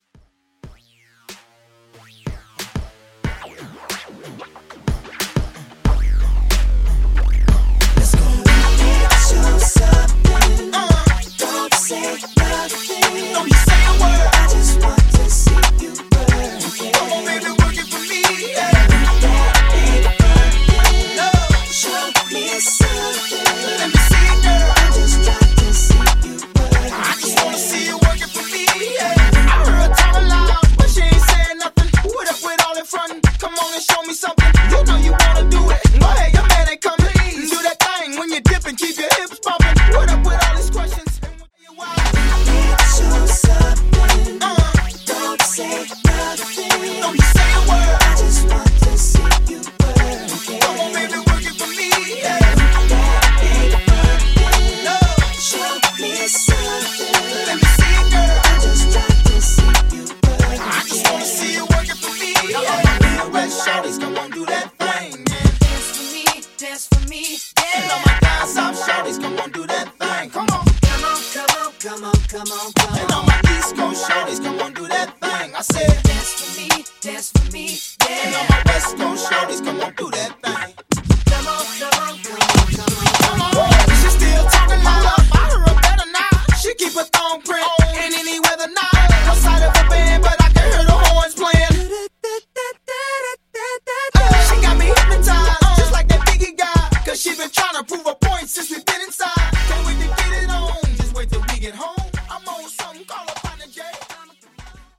Genres: 90's , TOP40 Version: Clean BPM: 125 Time